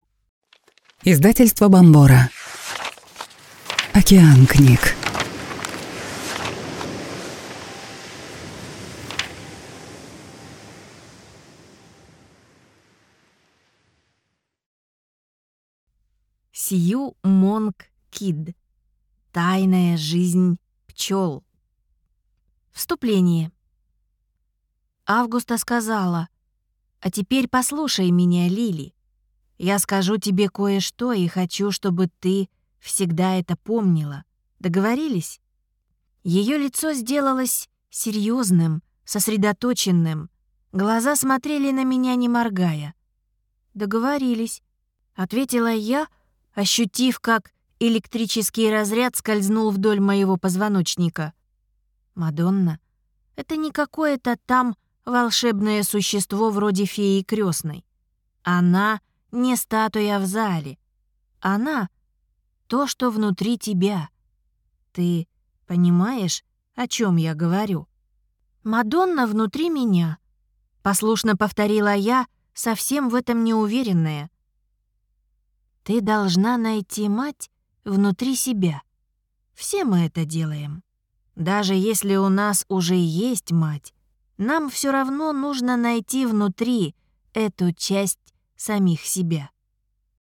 Аудиокнига Тайная жизнь пчел | Библиотека аудиокниг